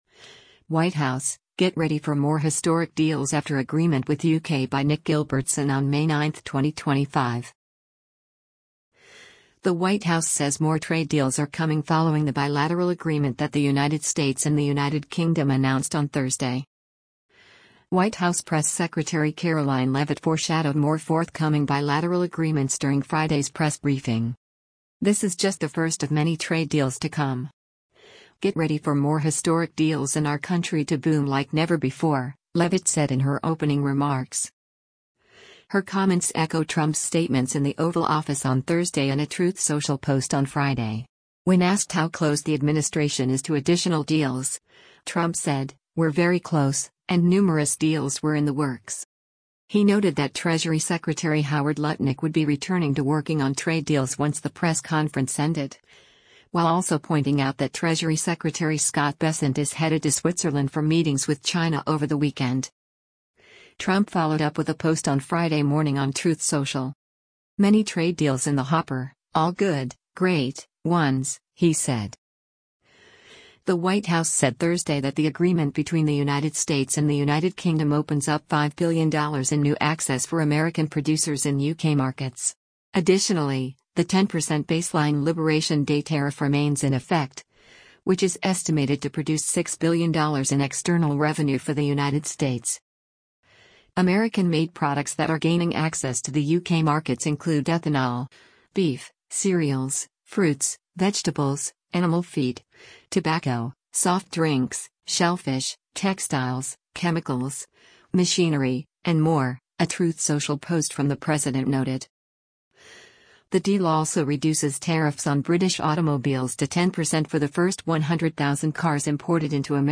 White House press secretary Karoline Leavitt foreshadowed more forthcoming bilateral agreements during Friday’s press briefing.